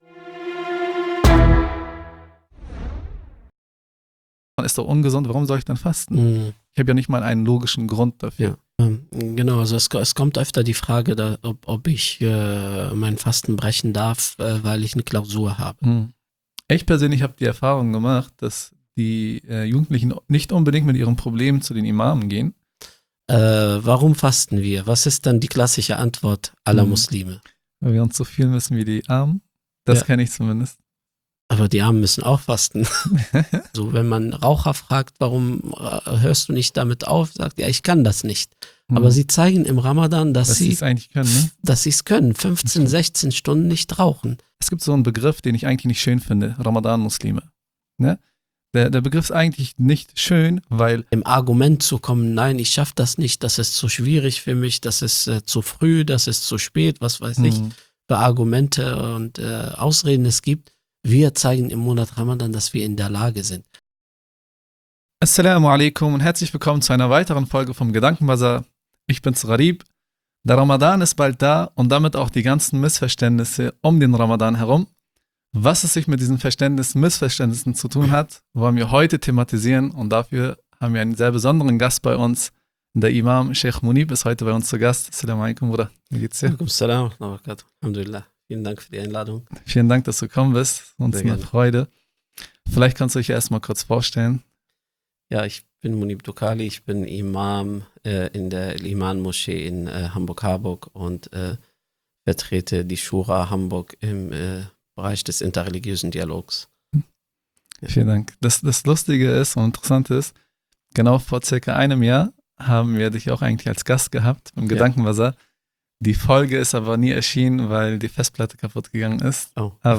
In dieser Folge sprechen wir mit unserem neuen Gast über den heiligen Monat Ramadan und welche Missverständnisse heute noch bei vielen Muslimen bestehen.